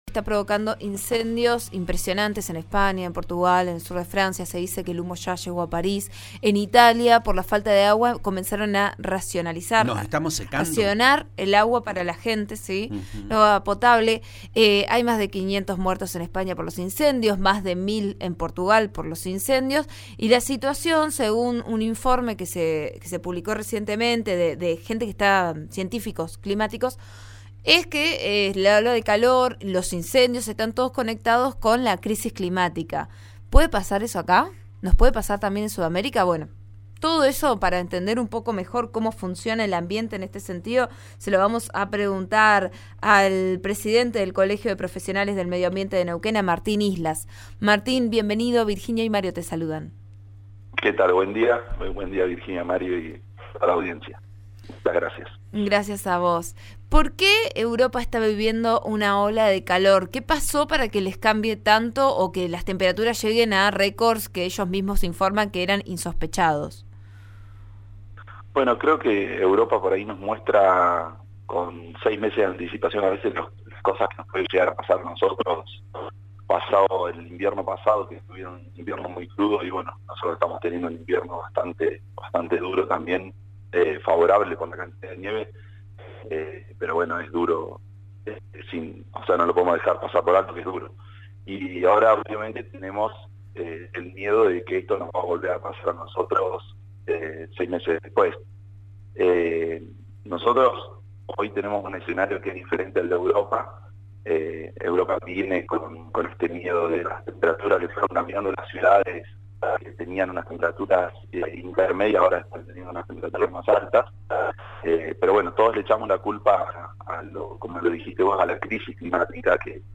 explicó en una nota con Vos A Diario por RN Radio